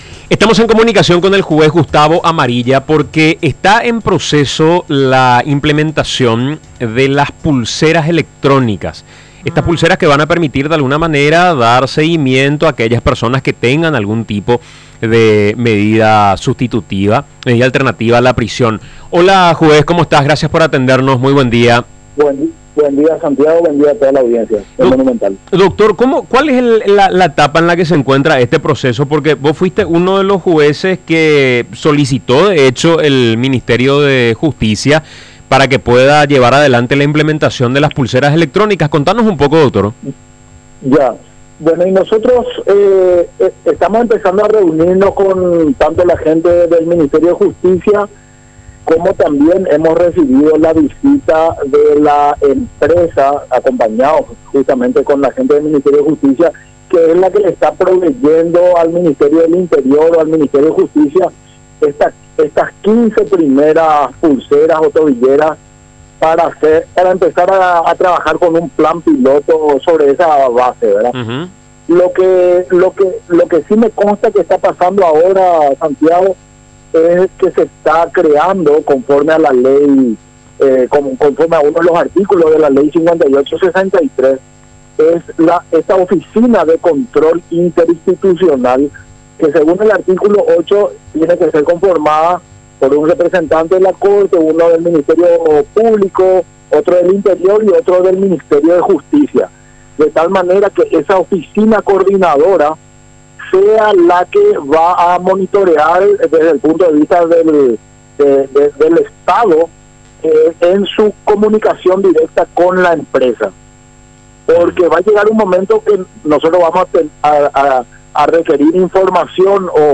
El juez Gustavo Amarilla habla del plan piloto para la implementación de la pulsera o tobillera electrónica para personas beneficiadas con medidas alternativas a la prisión.